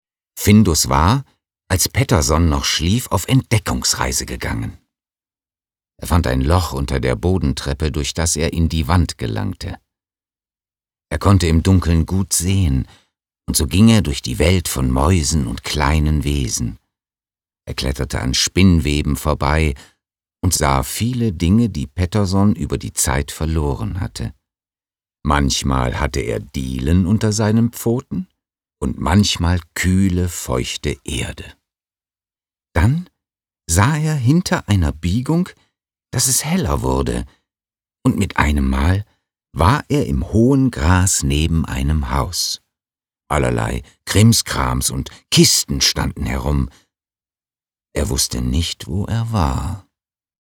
Sprecher _Voice Actor
Sie suchen nach einer sympathischen, tragfähigen Stimme....
Mikrofon: Rode NT-2A
Kinder